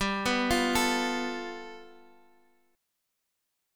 G9 chord